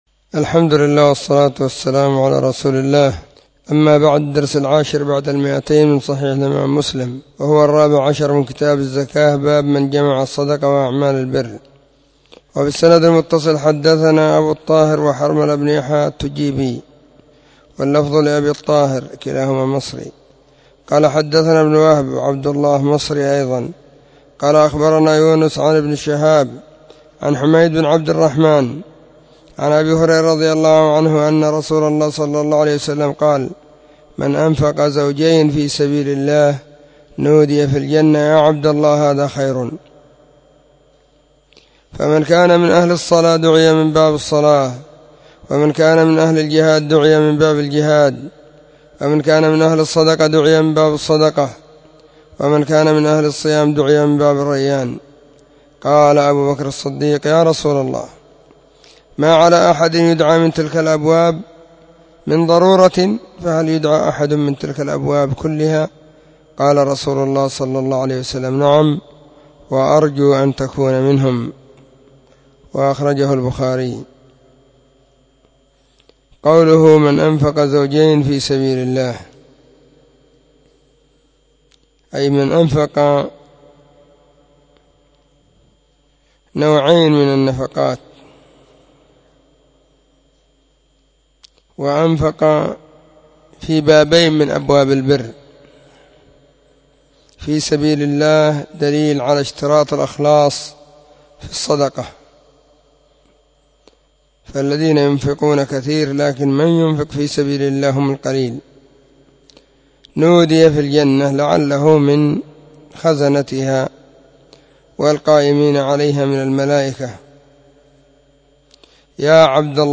📢 مسجد الصحابة – بالغيضة – المهرة، اليمن حرسها الله.
كتاب-الزكاة-الدرس-14.mp3